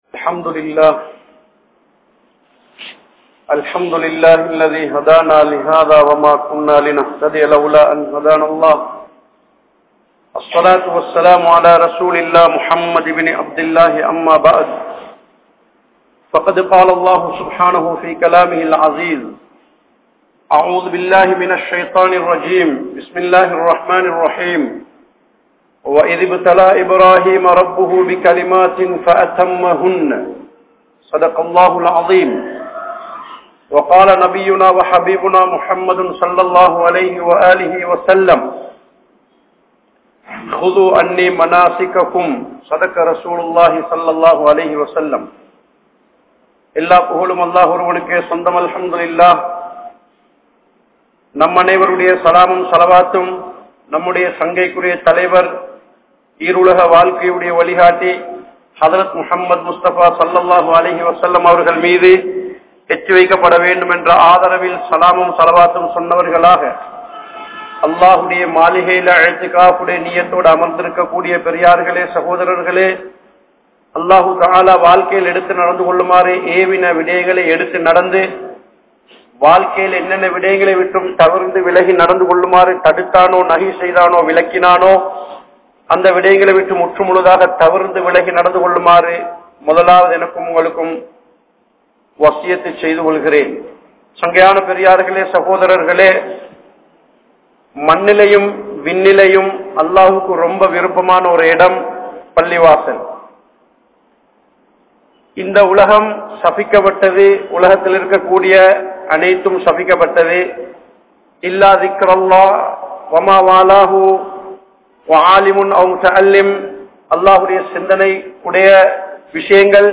Manithanai Vittru Ulaikum Samooham (மனிதனை விற்று உழைக்கும் சமூகம்) | Audio Bayans | All Ceylon Muslim Youth Community | Addalaichenai